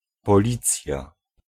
The Police (Polish: Policja, [pɔˈlit͡sja]
Pl-policja.ogg.mp3